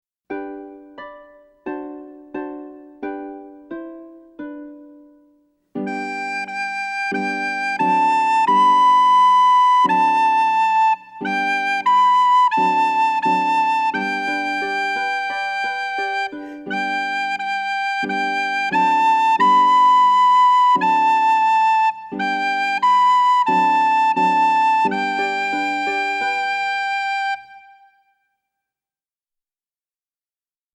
Voicing: Book and Audio Access